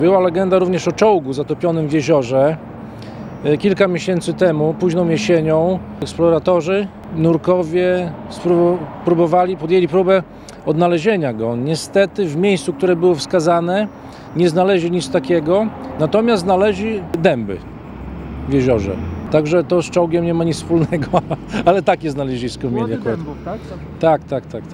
Czołgu również poszukiwano, ale jak opowiada Mariusz Grygieńć, wójt gminy, zamiast maszyny nurkowie znaleźli dębowe kłody.